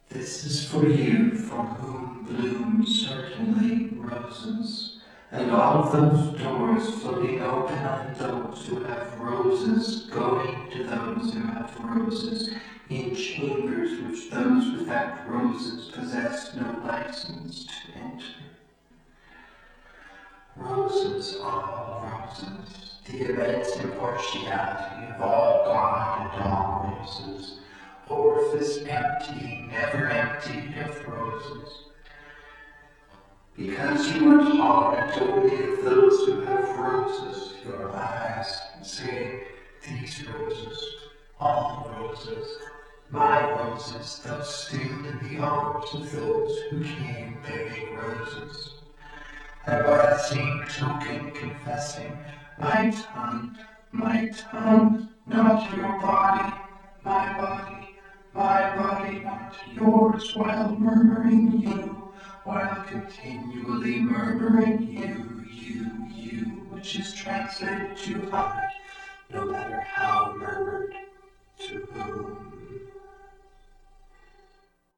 Source: complete text (8:00-9:15)